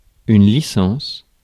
Ääntäminen
France: IPA: /li.sɑ̃s/